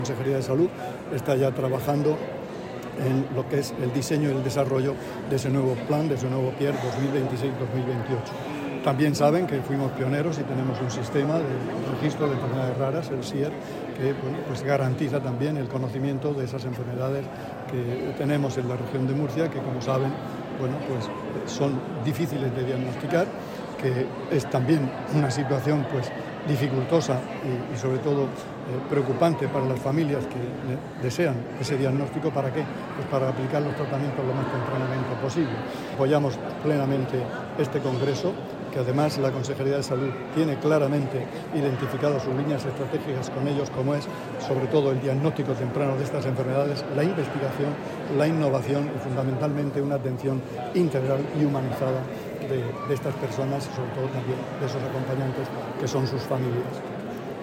Sonido/ Declaraciones del consejero de Salud en el Congreso nacional de Enfermedades Raras